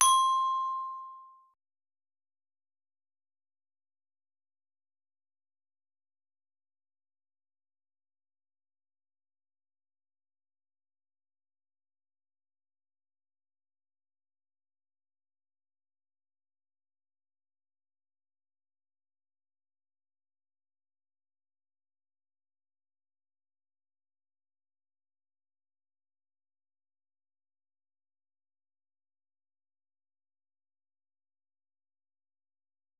Glockenspiel